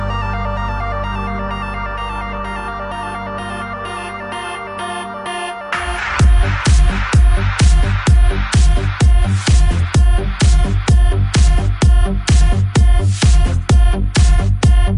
Ambiant Vocal
aural psynapse loop.mp3